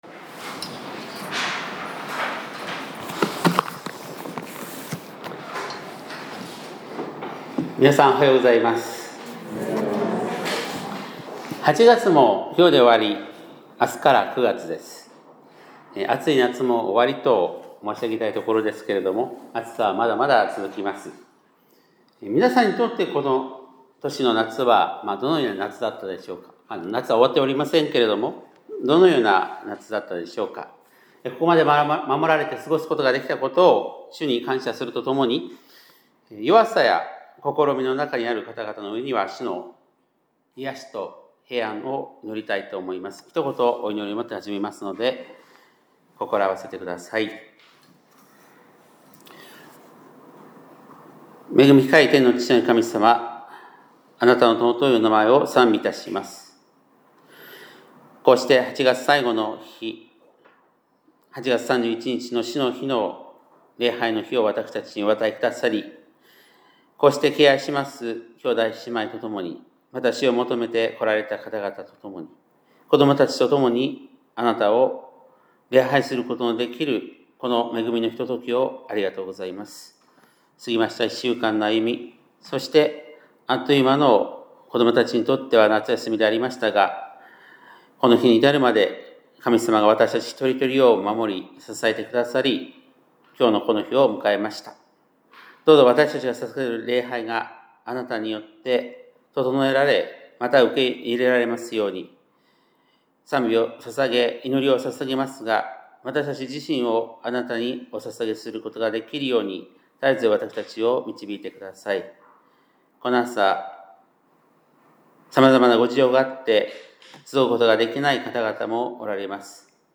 2025年8月31日（日）礼拝メッセージ - 香川県高松市のキリスト教会
2025年8月31日（日）礼拝メッセージ